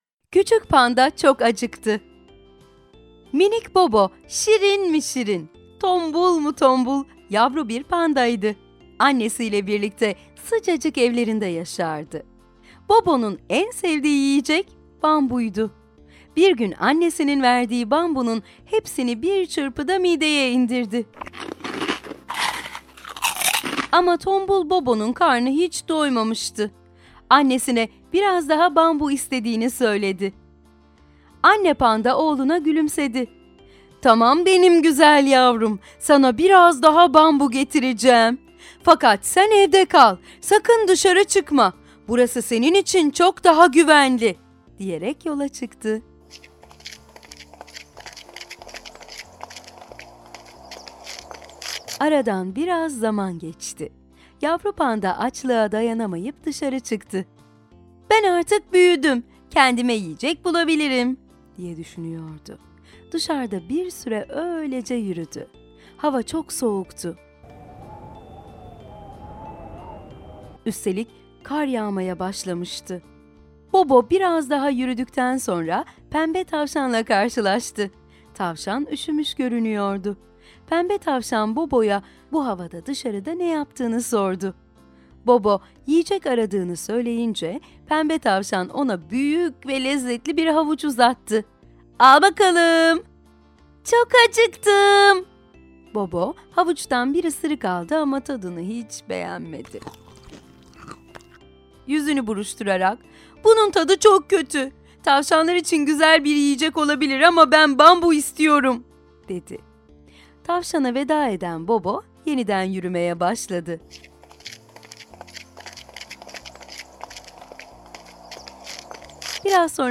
Sesli Kitap